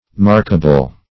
Markable \Mark"a*ble\